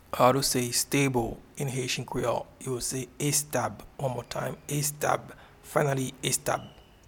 Pronunciation and Transcript:
stable-in-Haitian-Creole-estab.mp3